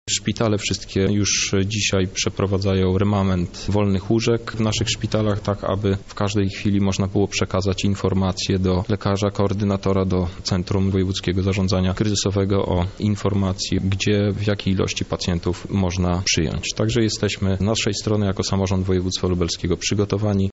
Poprosiłem dyrektorów aby każdy pacjent został przyjęty – mówi marszałek Hetman